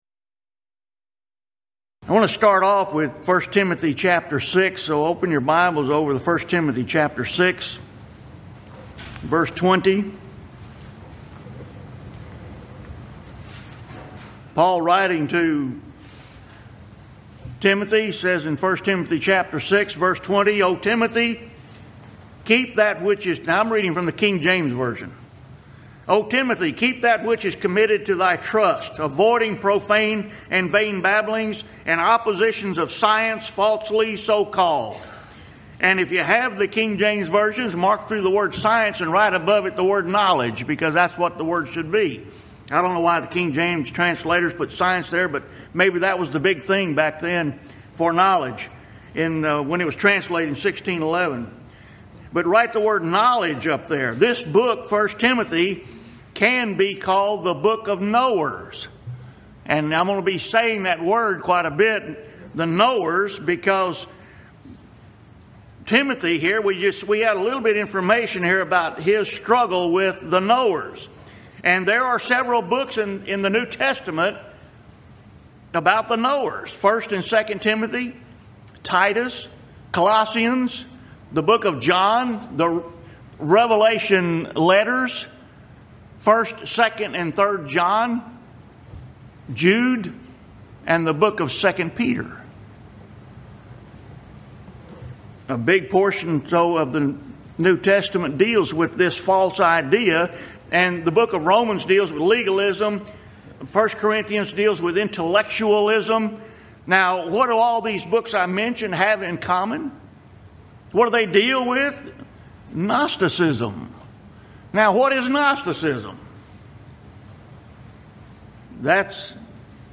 Event: 2015 South Texas Lectures